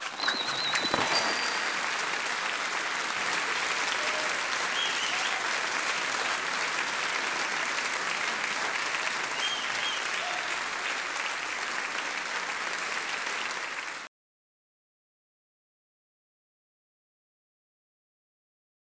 Menu Scene Clear Sound Effect
menu-scene-clear.mp3